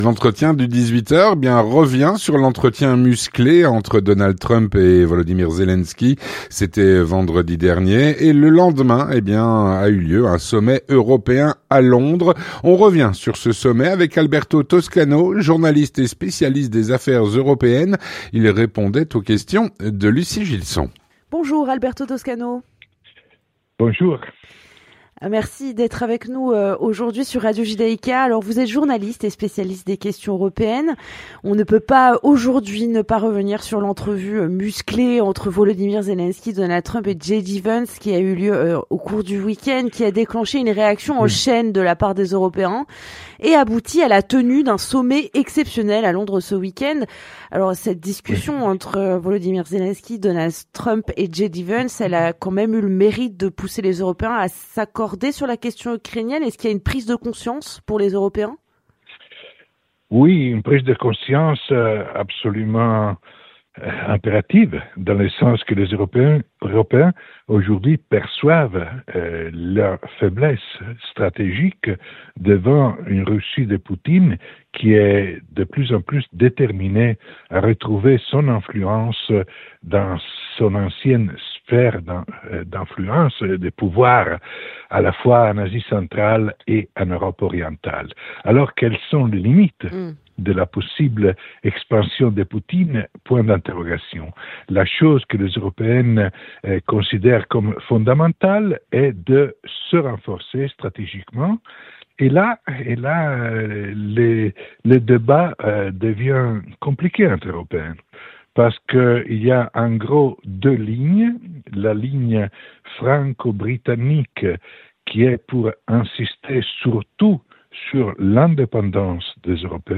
L'entretien du 18H - Entretien musclé Trump-Zelensky : le Jour d’après.